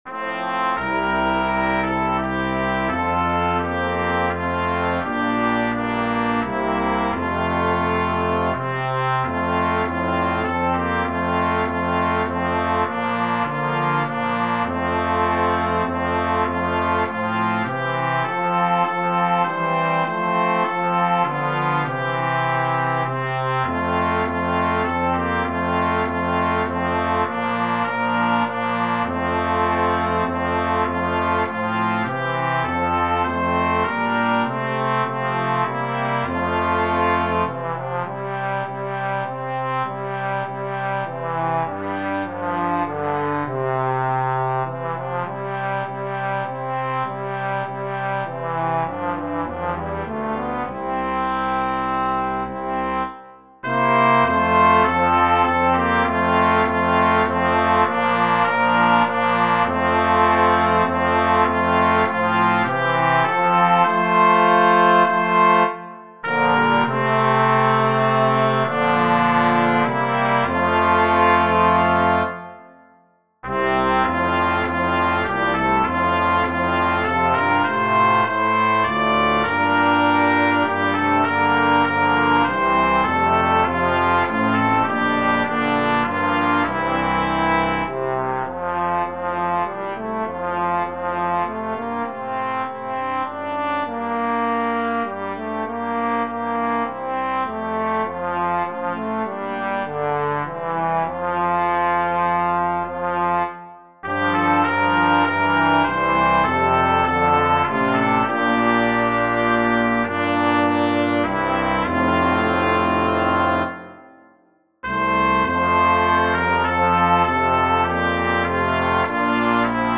Trumpet,3 Trombones,Tuba